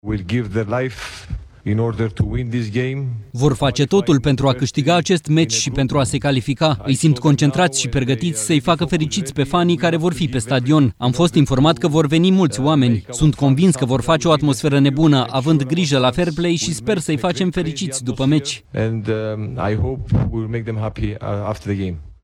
30iul-12-Charalambous-–-Vrem-calificarea-Tradus.mp3